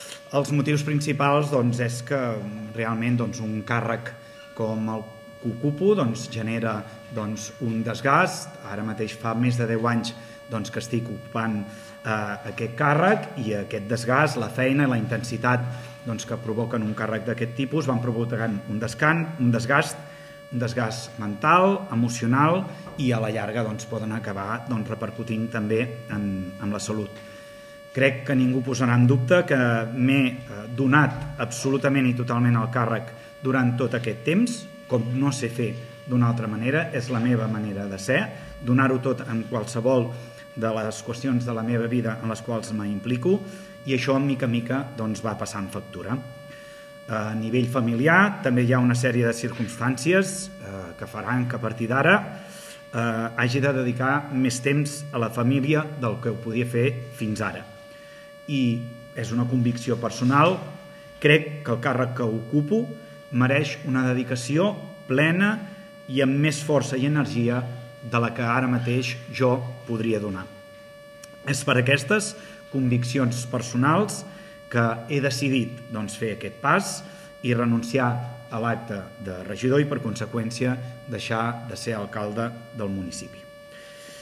L’anunci s’ha fet en una roda de premsa convocada d’urgència aquest mateix matí a la sala de plens de l’Ajuntament.